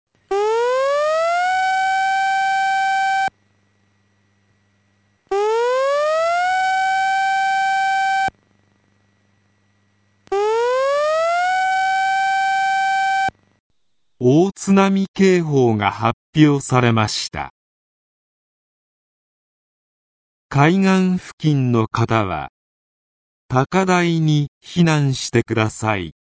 全国瞬時警報システム（J－ALERT）による放送例
大津波警報（MP3：132.8KB） (音声ファイル: 132.8KB)